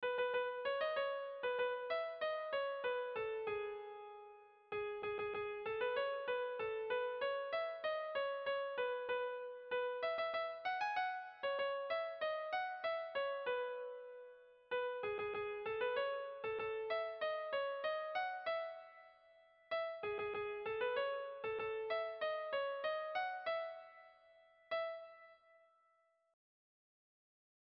Tragikoa
Zortziko handia (hg) / Lau puntuko handia (ip)
ABDB